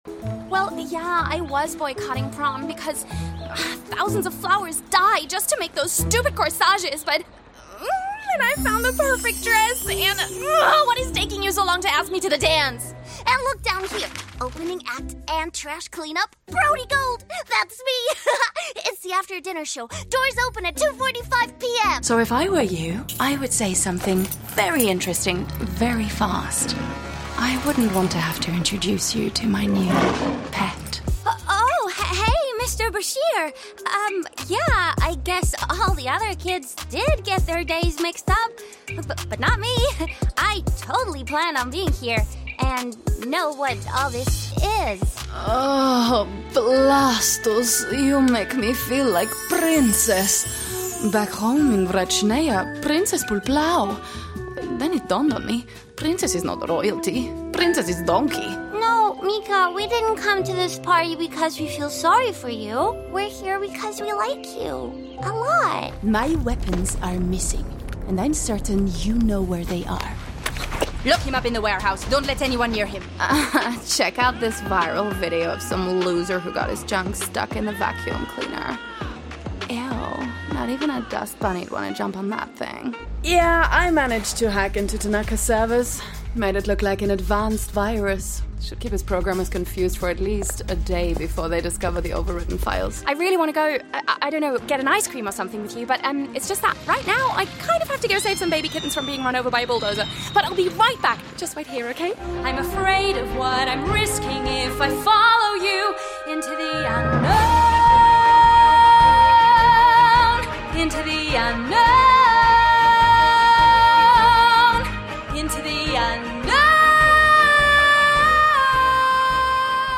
Animation
My voice is relatable, contemporary and youthful with a warm and textured sound.